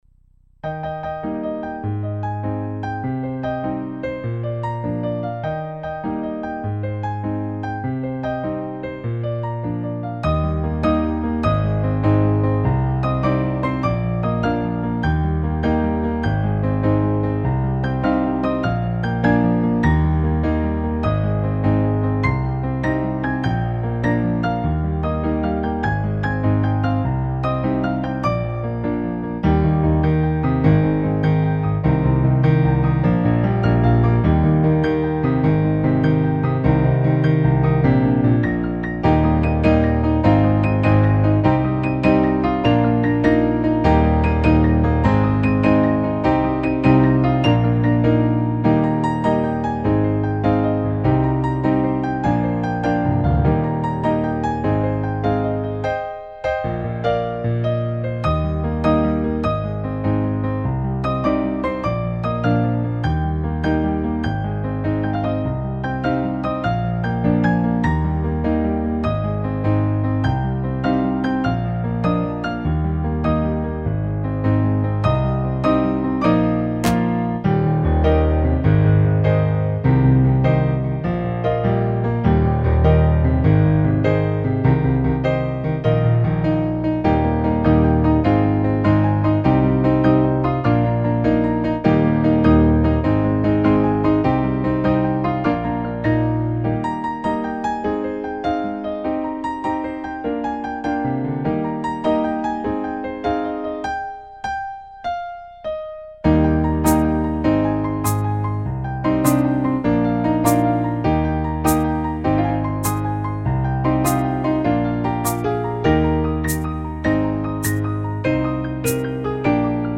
instrumentation: Piano Duet